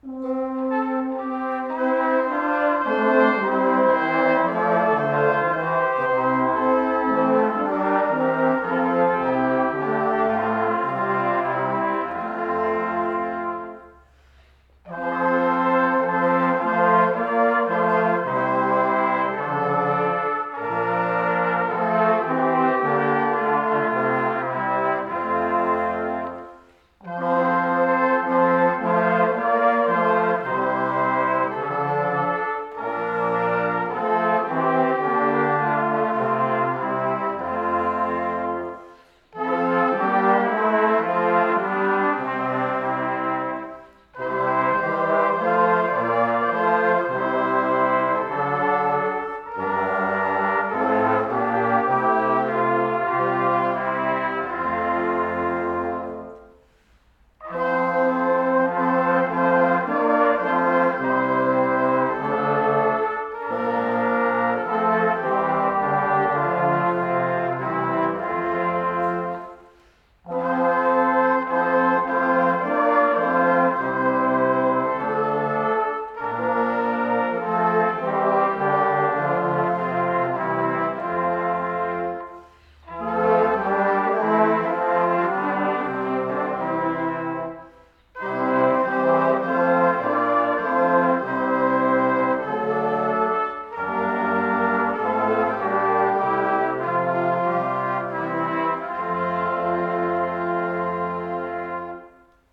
Posaunenchor Weihnachten 2020
Damit Sie nicht auf festliche Klänge verzichten müssen, haben wir bei unserer letzten Probe vier Weihnachtslieder in der Scheune eingespielt, sie können hier angehört werden, vielleicht haben Sie Lust dazu mitzusingen!